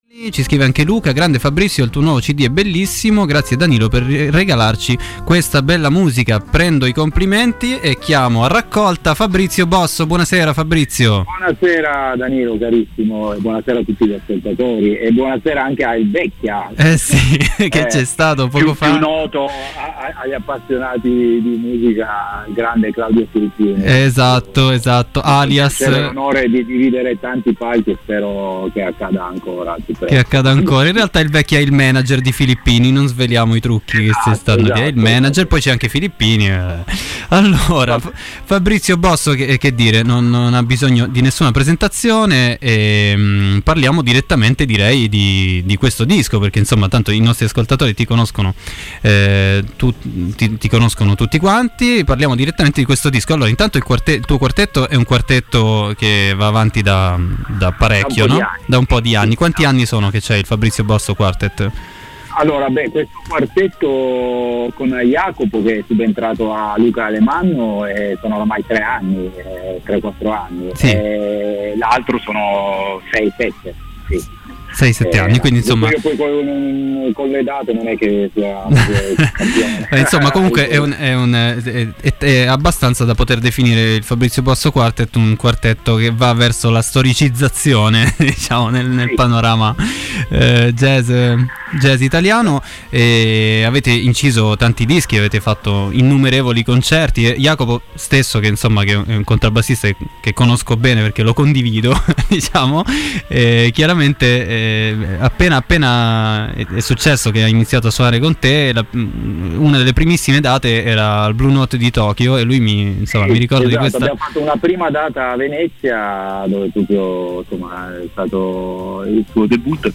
Intervista a Fabrizio Bosso.